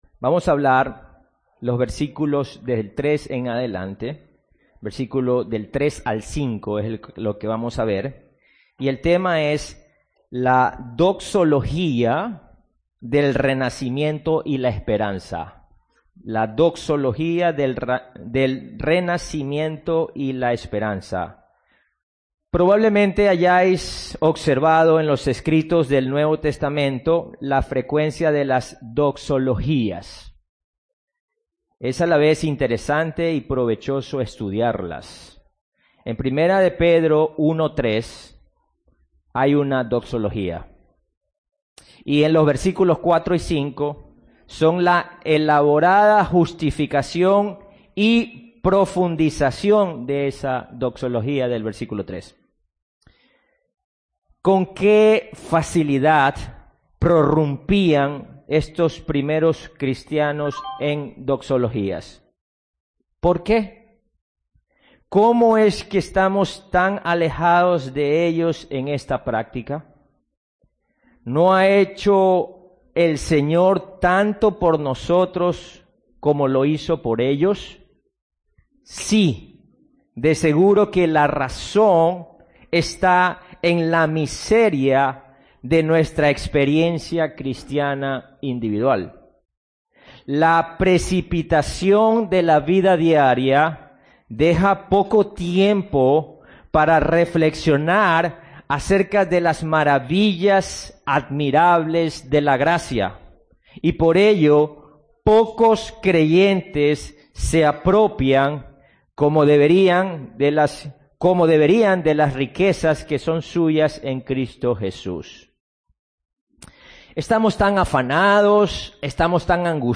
Audio del sermón
doxologia-del-renacimiento-y-la-esperanza.mp3